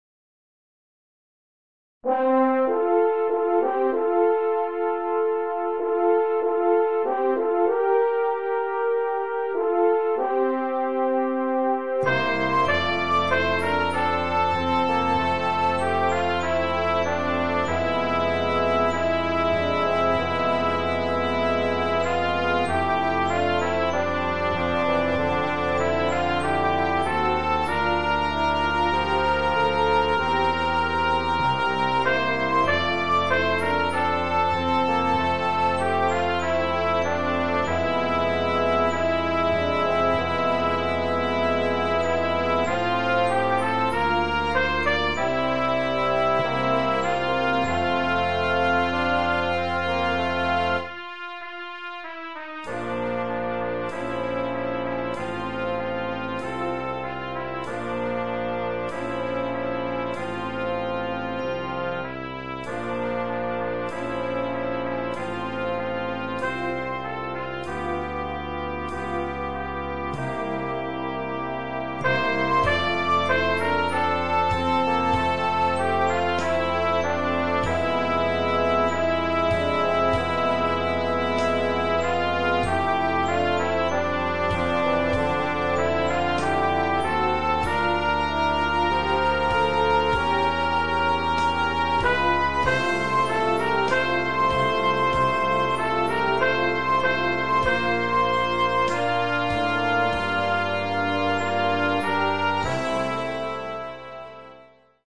für Jugendblasorchester
Besetzung: Blasorchester